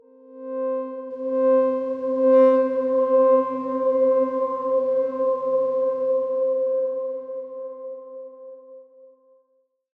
X_Darkswarm-C4-pp.wav